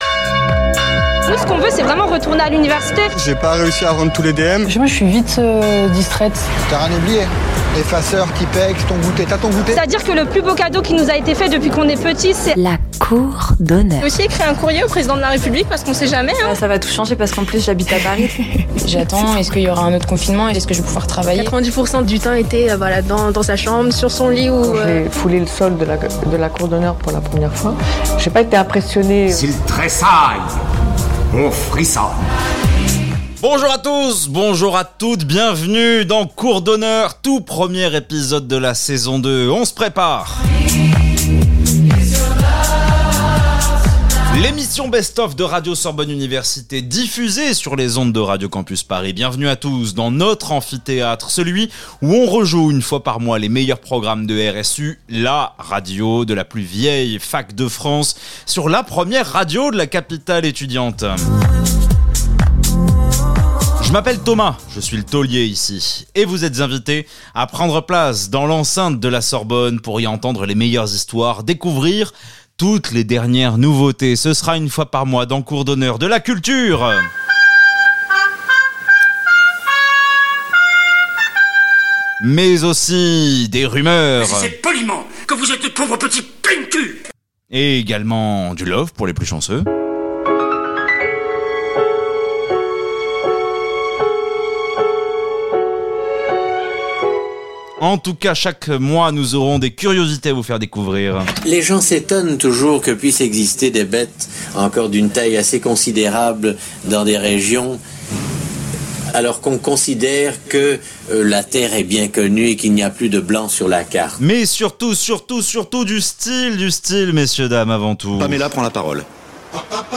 Les étudiants de Radio Sorbonne Université piratent Radio Campus Paris